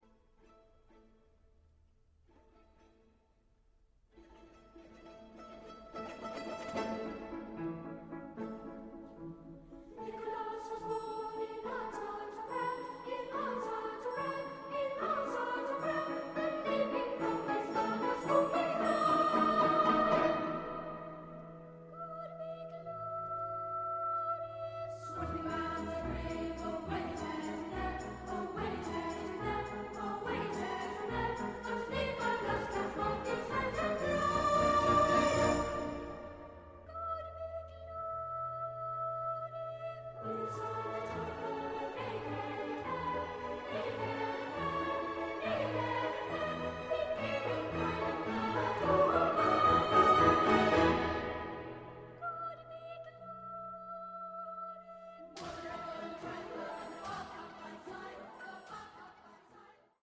treble